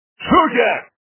Мужской голос - Шухер Звук Звуки Голос - Шухер
» Звуки » Люди фразы » Мужской голос - Шухер
При прослушивании Мужской голос - Шухер качество понижено и присутствуют гудки.